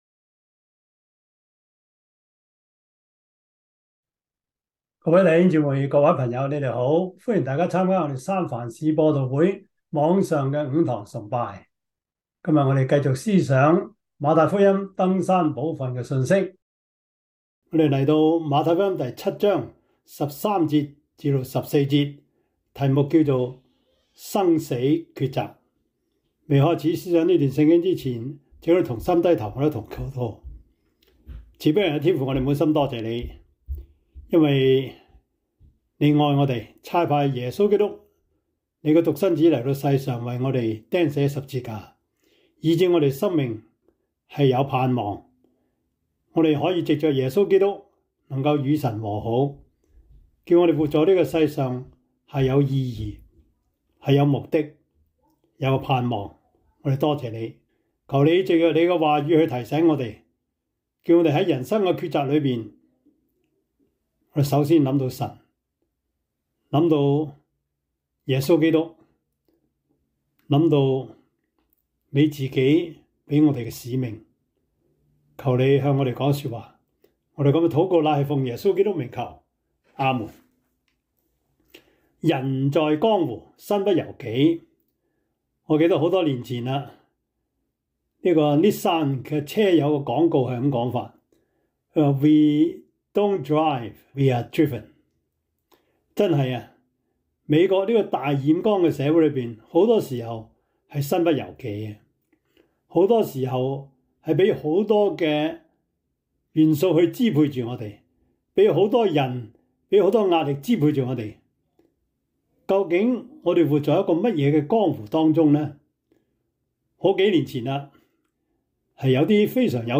馬太福音 7:13-14 Service Type: 主日崇拜 馬太福音 7:13-14 Chinese Union Version
Topics: 主日證道 « 耶和華與他同在-職場順境?逆境?